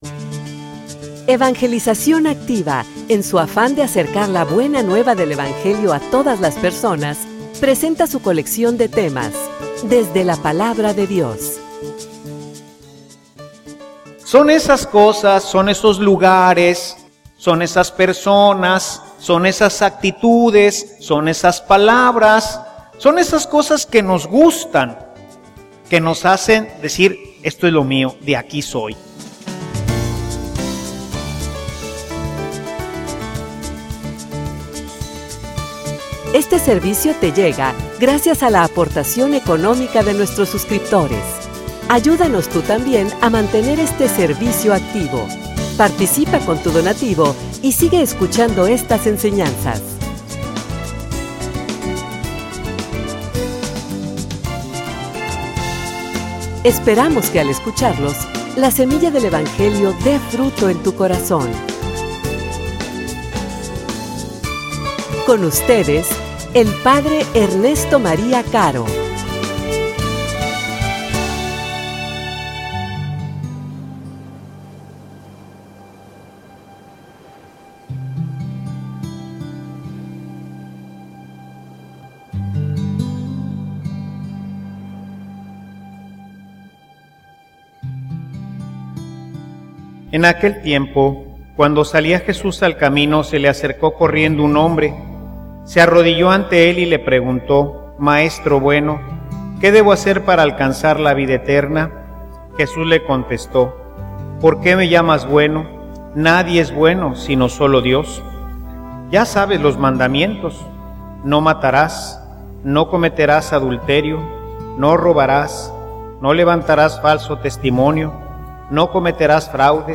homilia_Nuestros_conejitos.mp3